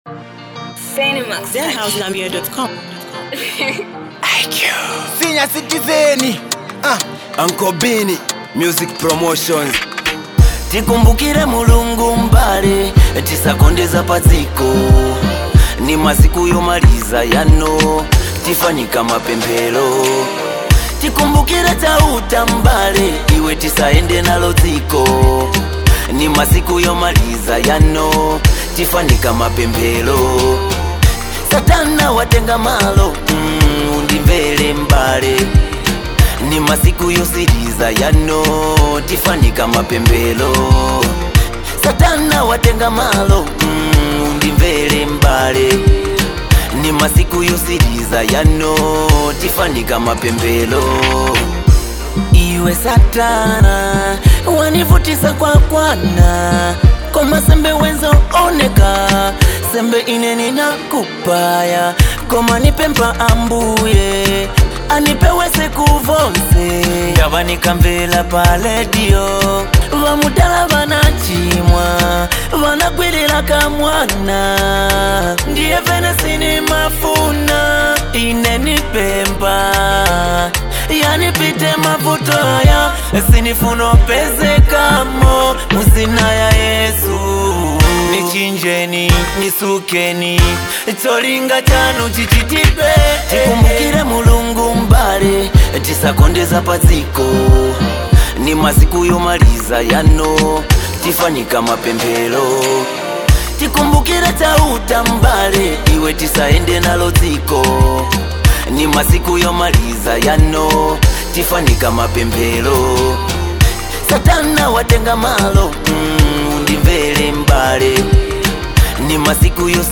a touching and reflective song that calls for remembrance